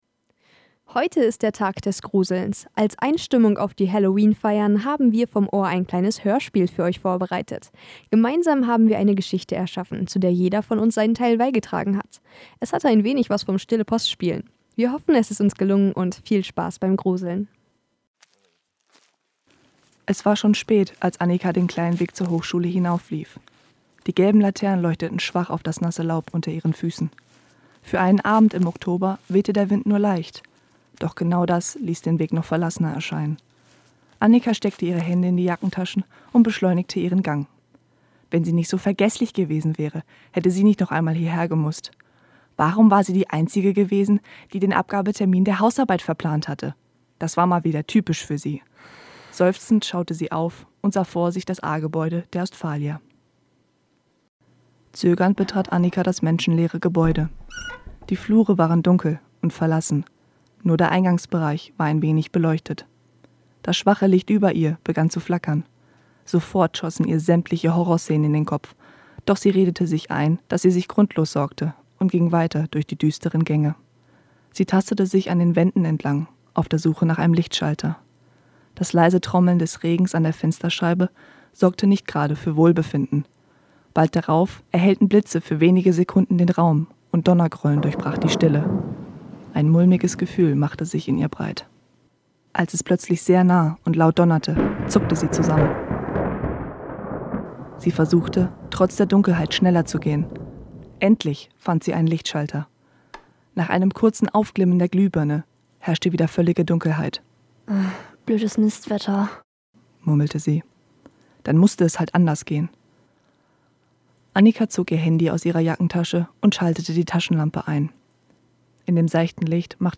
Anlässlich zum Tag des Horrors haben wir ein Hörspiel vorbereitet!